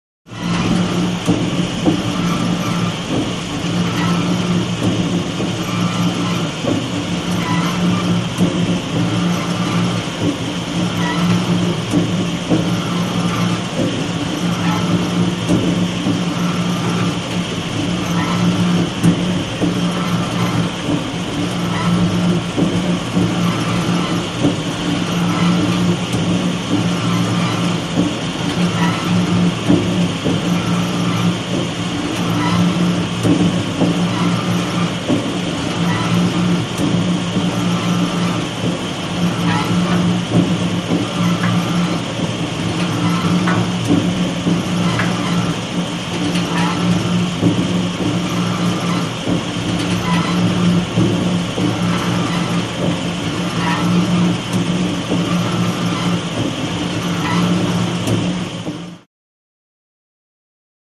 Oil Pump | Sneak On The Lot
Oil Pump; Oil Pump; Motor / Fan Noises, Rhythmic Squeak / Pumping Sound, Chain Noise, Medium Perspective.